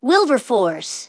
synthetic-wakewords
ovos-tts-plugin-deepponies_Bart Simpson_en.wav